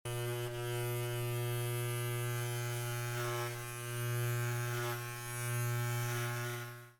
A segment of the "Buzzcut" audio file. No further audio effects were added. This sound is correlated with the letter "l" on the computer keyboard.